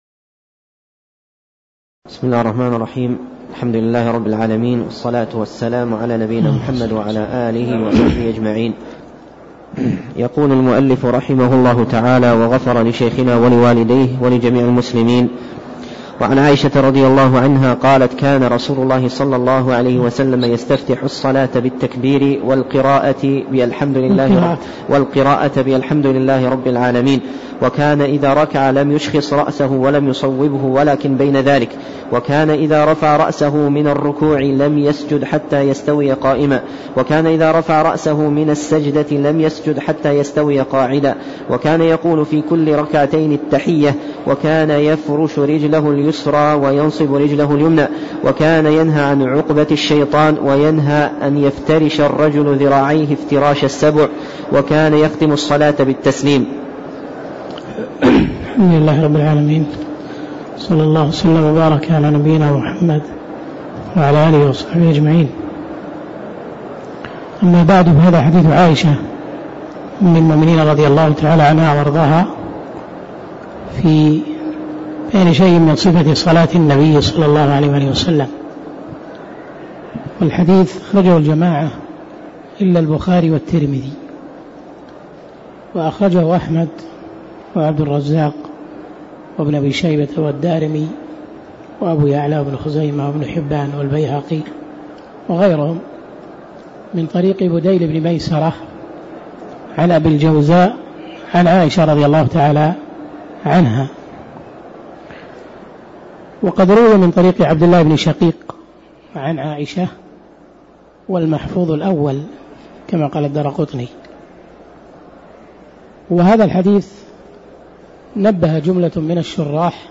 تاريخ النشر ٦ شعبان ١٤٣٦ هـ المكان: المسجد النبوي الشيخ